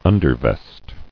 [un·der·vest]